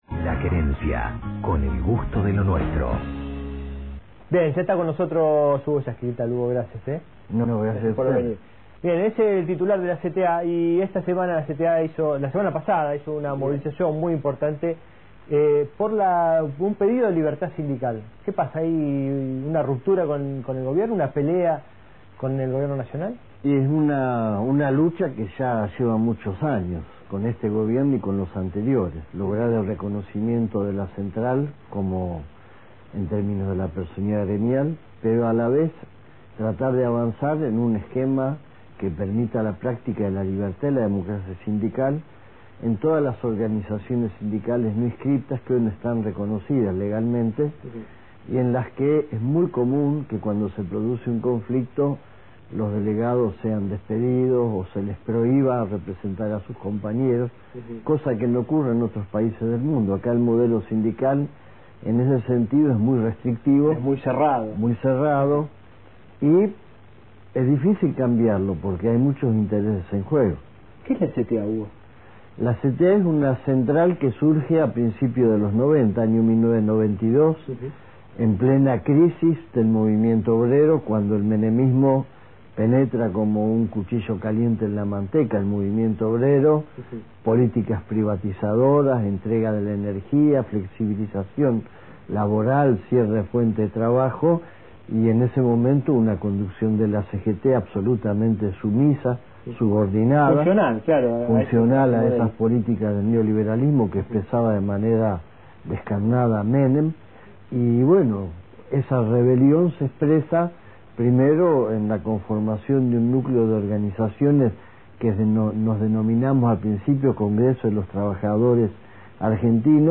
- 24/05/08 - Am�rica 24 - Programa X Y Entrevista a Hugo Yasky